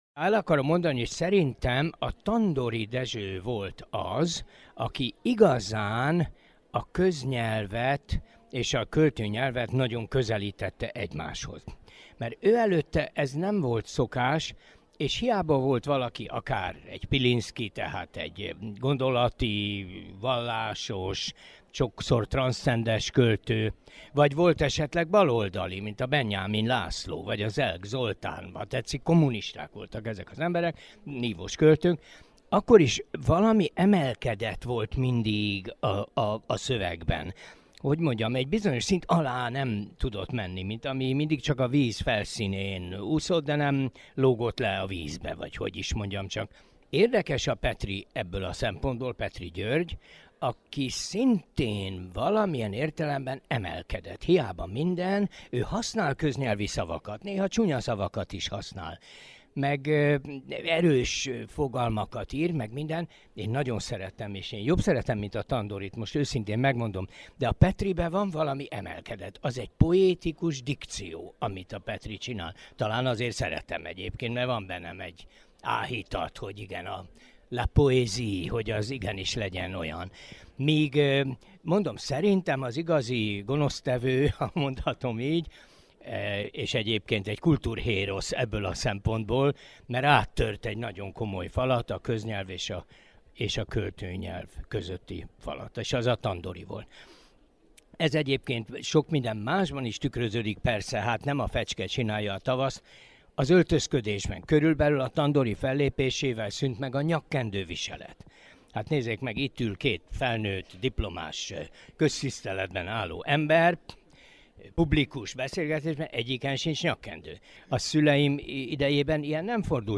A 23-ik Marosvásárhelyi Nemzetközi Könyvvásár Irodalmi szellemiségi Kávéházában köznyelvről, költői nyelvről, nyelvújulásról beszélgetett Nádasdy Ádám költő, nyelvész, műfordítóval Kovács András Ferenc költő.